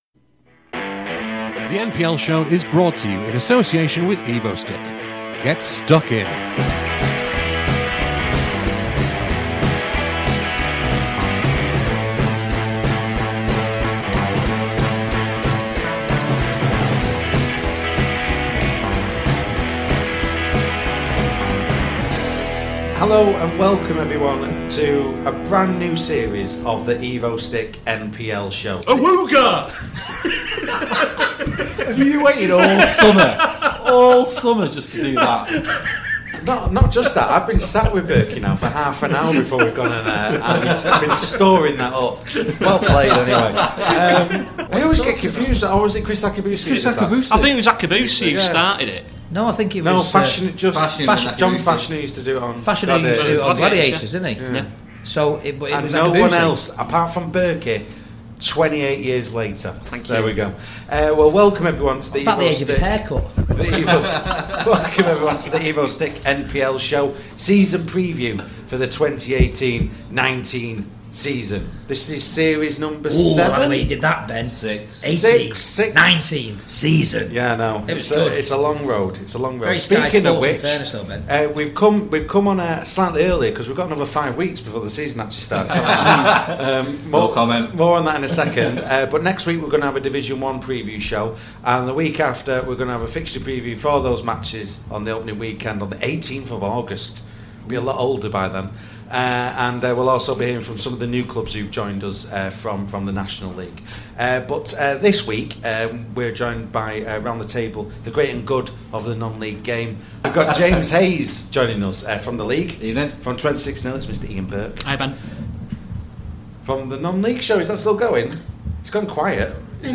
This programme was recorded at the Heaton Hops on Wednesday 8th August 2018.